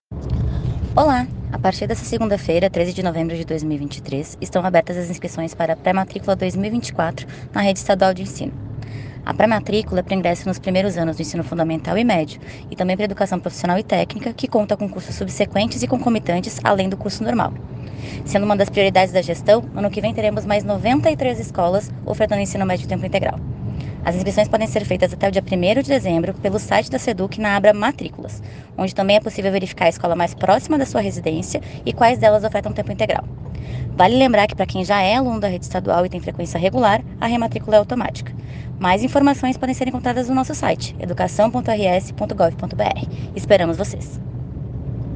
OUÇA AS ORIENTAÇÕES DA SECRETÁRIA ADJUNTA DE EDUCAÇÃO STEFANIE ESKERESKI
Secretária adjunta Stefanie Eskereski explica sobre o processo de matrículas na rede estadual